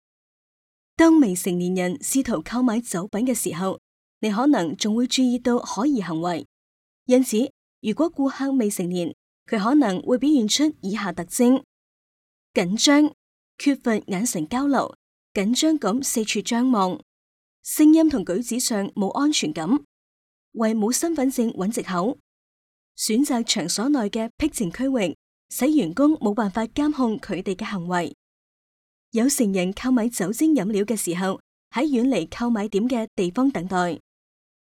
Cantonese_Female_003VoiceArtist_10Hours_High_Quality_Voice_Dataset